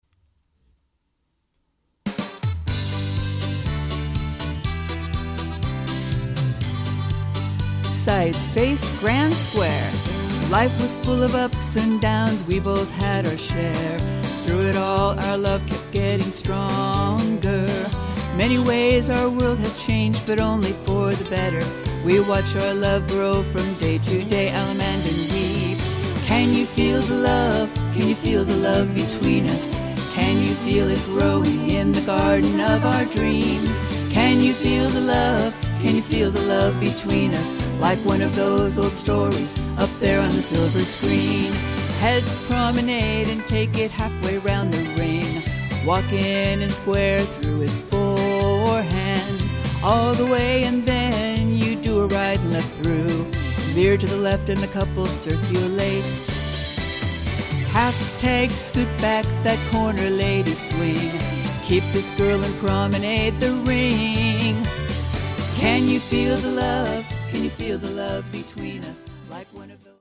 Vocal sample